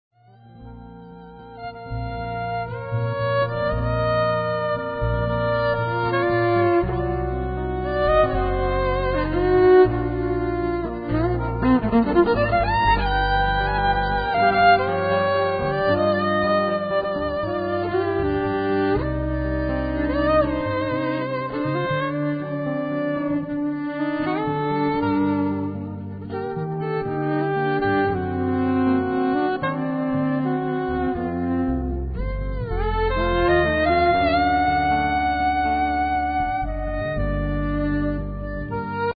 Violin
Sax, vocals
Grand Piano
Guitars
Live recording Nieuwe de la Mar theater Amsterdam